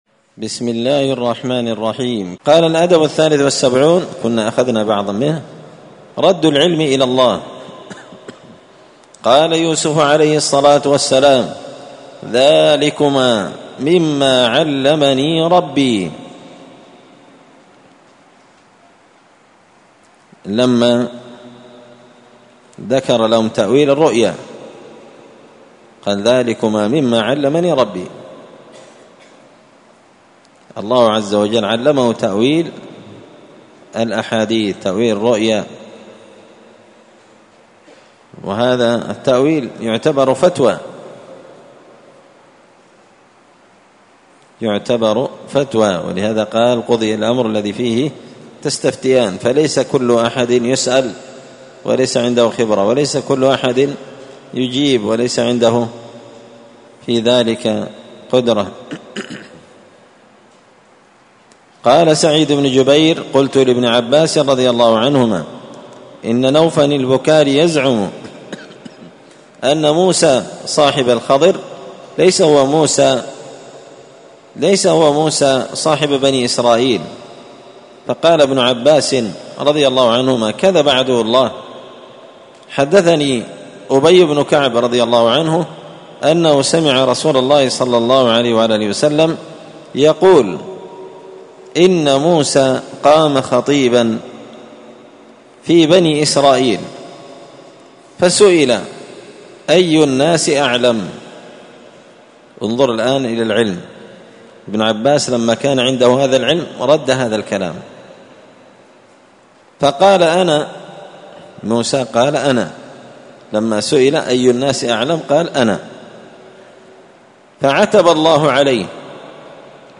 تعليق وتدريس الشيخ الفاضل: